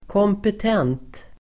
Uttal: [kåmpet'en:t]